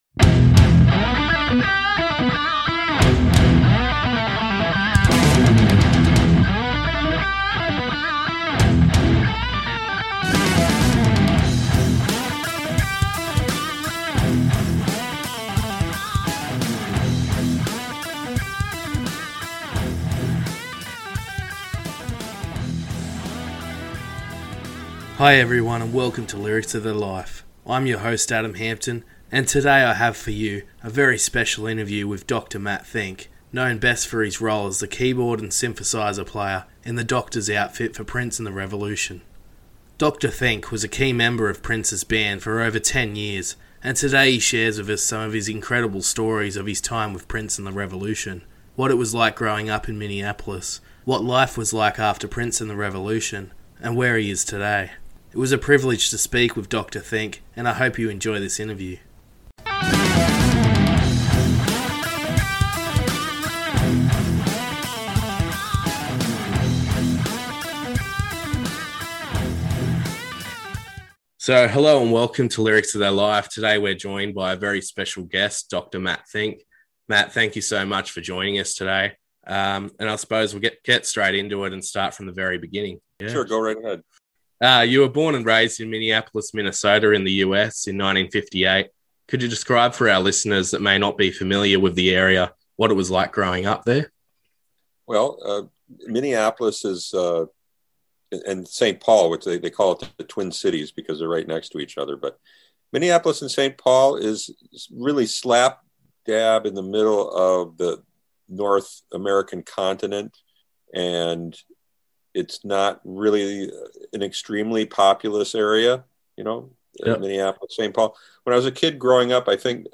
Interview with Dr. Fink of Prince & The Revolution
Special interview with Dr. Matt Fink known best for his time as the doctor outfit wearing keyboard and synthesizer player for Prince and The Revolution.